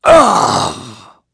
Clause_ice-Vox_Damage_03.wav